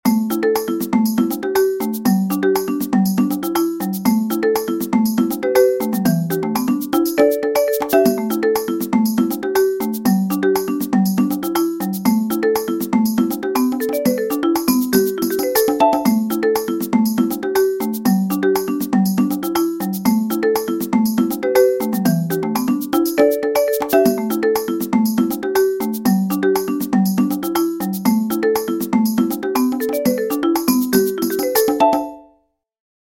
5edo_groove.mp3